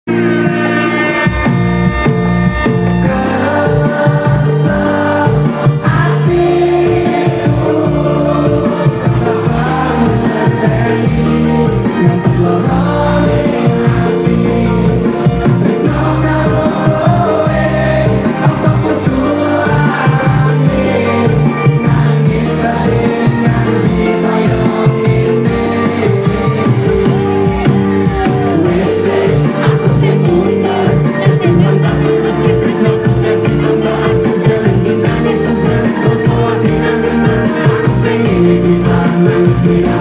di alun alun Kudus